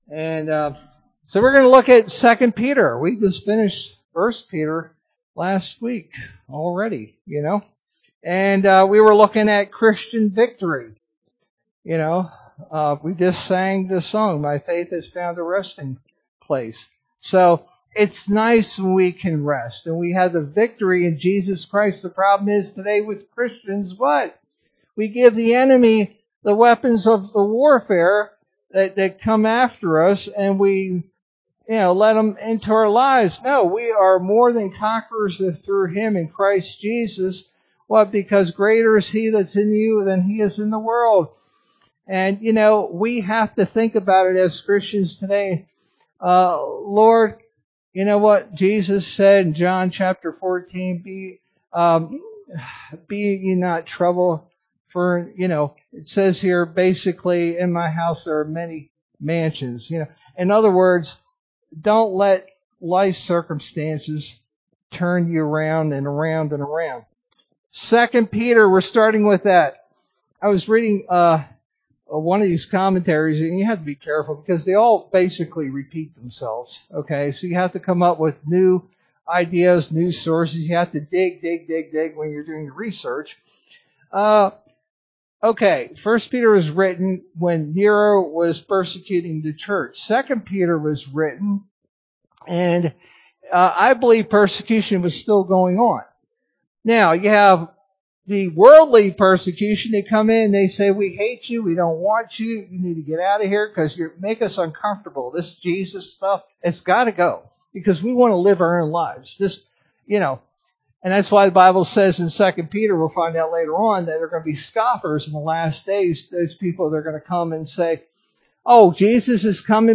Sermon verse: 2 Peter 1:1-11